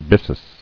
[bys·sus]